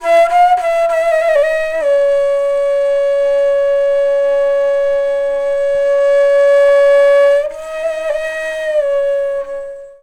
FLUTE-B12 -L.wav